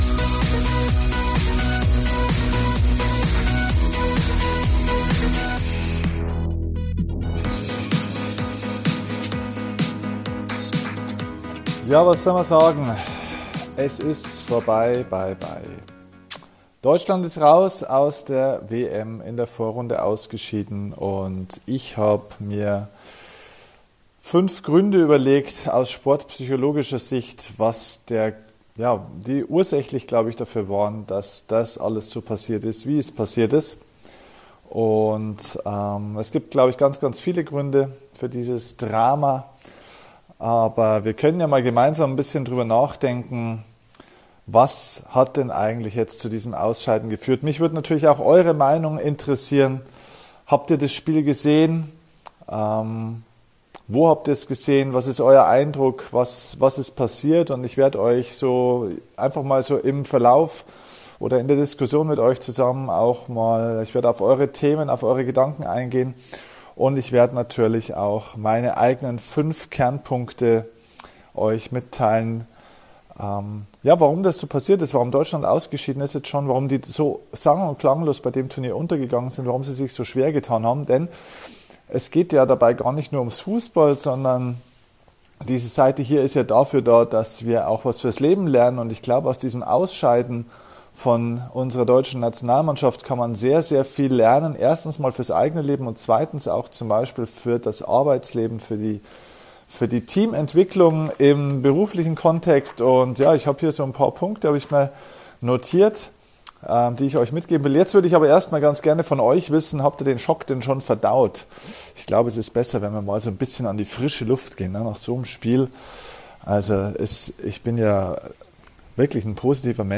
In dieser Folge erfährst Du von mir die 5 Gründe, warum die Nationalmannschaft meiner Meinung so früh bei der Weltmeisterschaft gescheitert ist. Diese Folge ist etwas besonders: zum einen natürlich wegen der riesen Überraschung bei der Weltmeisterschaft und zum anderen, weil es der Audiomitschnitt eines Livestreams auf Facebook ist.